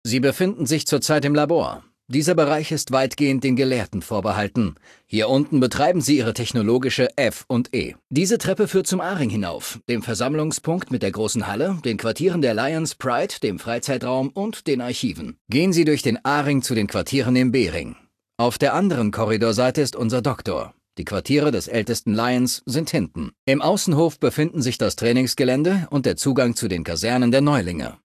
Datei:Maleadult01default dialogueci citknightdirect 00026c42.ogg
in: Fallout 3: Audiodialoge Datei : Maleadult01default dialogueci citknightdirect 00026c42.ogg Quelltext anzeigen TimedText Versionsgeschichte Diskussion Version vom 10.